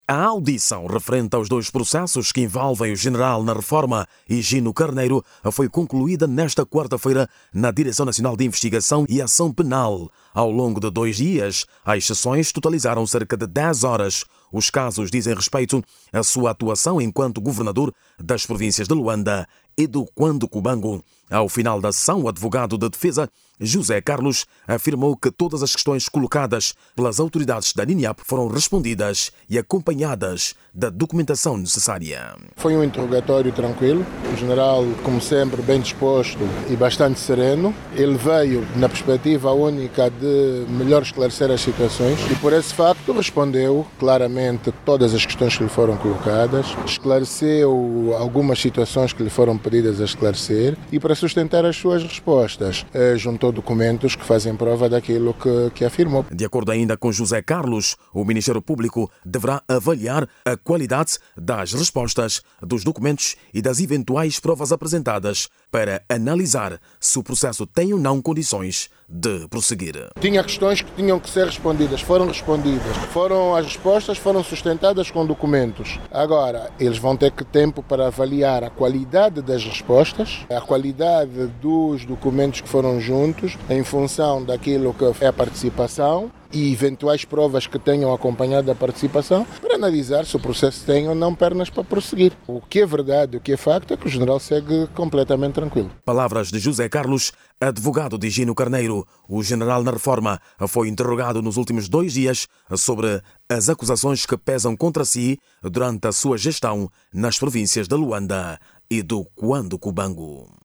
Jornalista